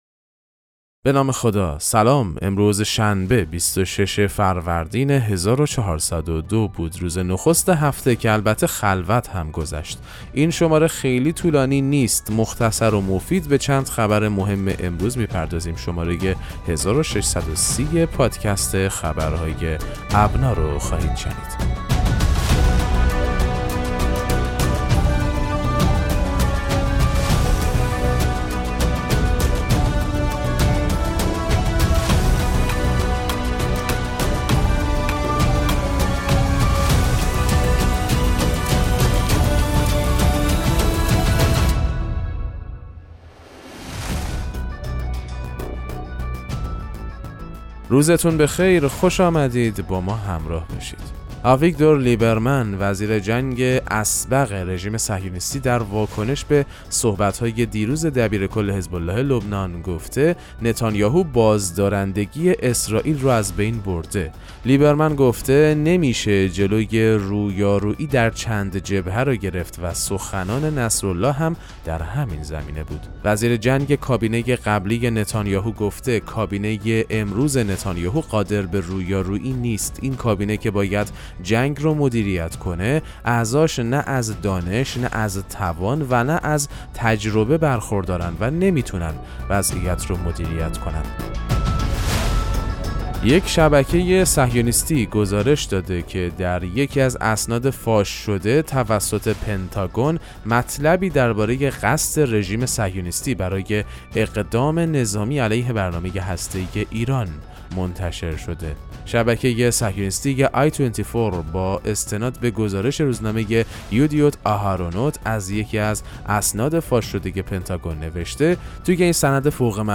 پادکست مهم‌ترین اخبار ابنا فارسی ــ 26 فروردین 1402